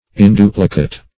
Search Result for " induplicate" : The Collaborative International Dictionary of English v.0.48: Induplicate \In*du"pli*cate\, a. (Bot.)